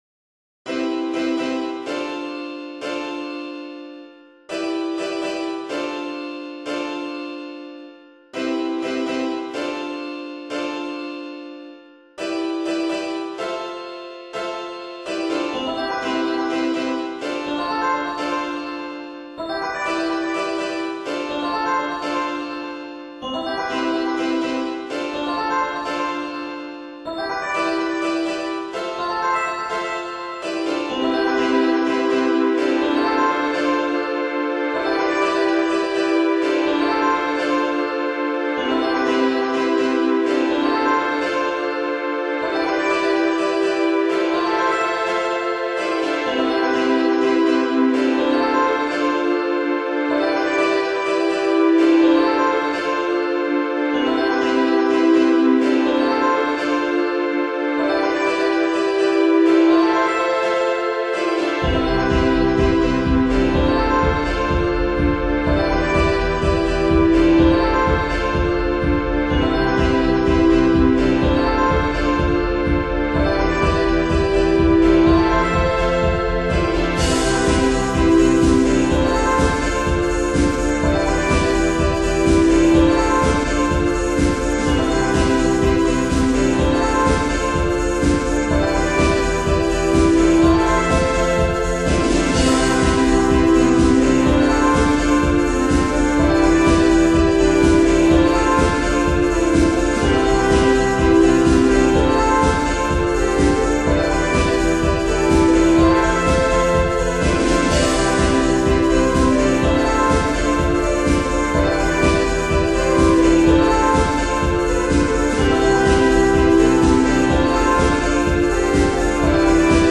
デジタルロック（？）
エフェクトをかけてみたらライブっぽくなった…という三番煎じです（爆）